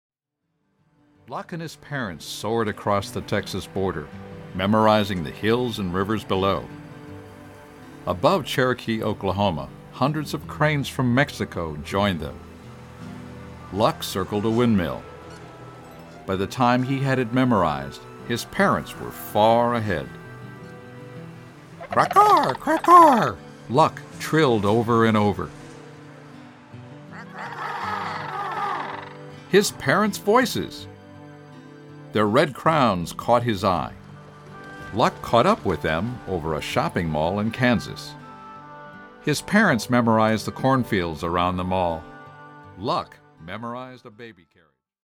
Home  >  Readalongs